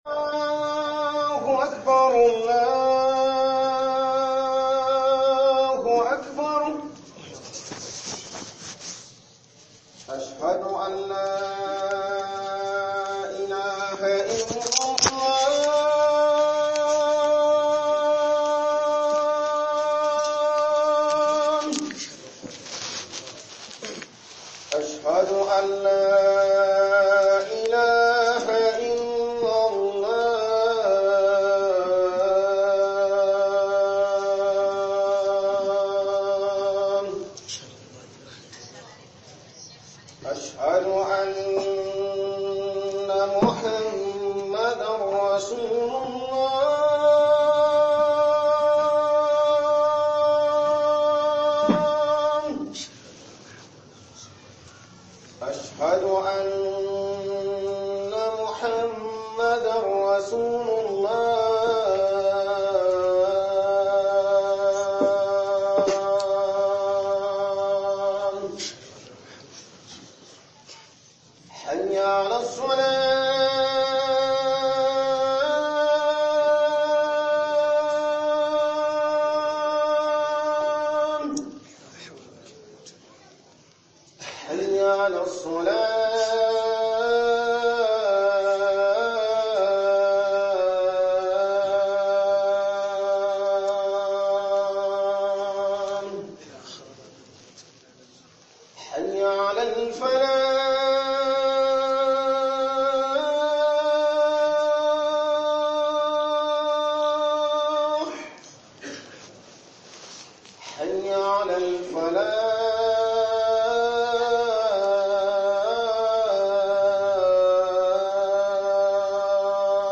Aikin Likita Ba Shagon Neman Kudi Bane 02 - HUƊUBOBIN JUMA'A